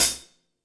Cymbol Shard 08.wav